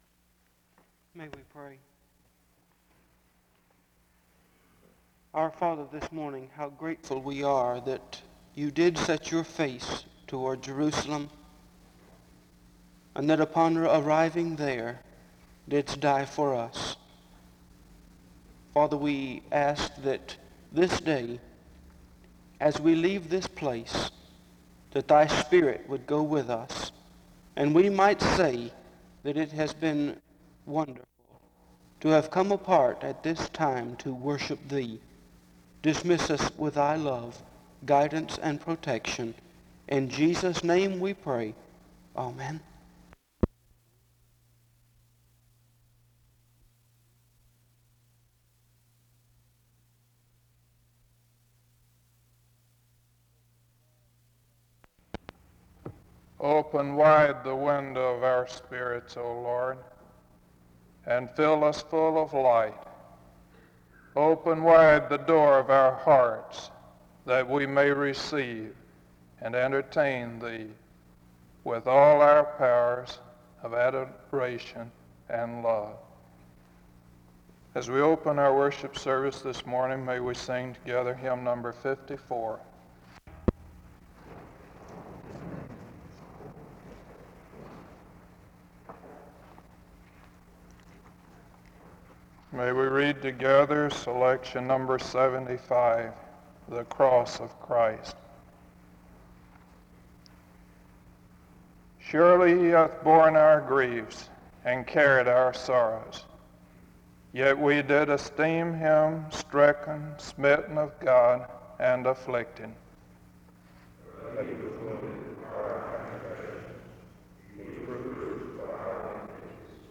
The service begins with a prayer and a responsive reading (0:00-3:27). After which, another prayer is offered (3:28-6:28).